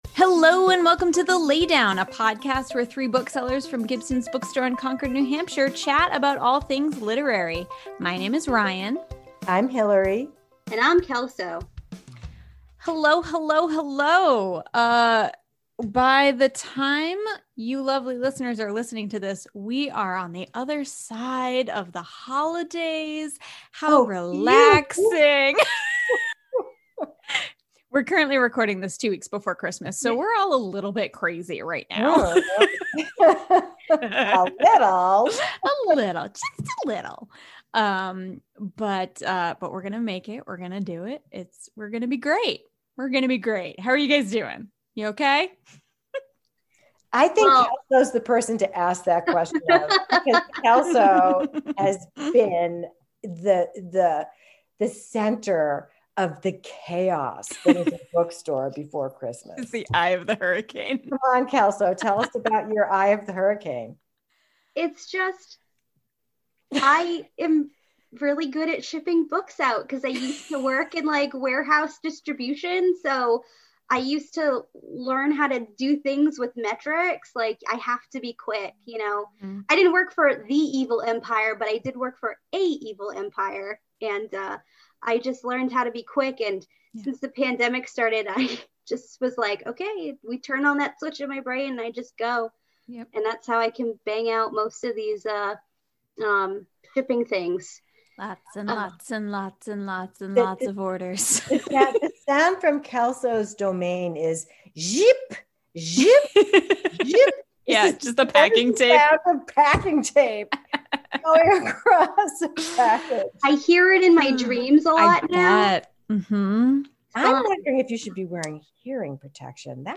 So Much TBR! (Recorded Remotely)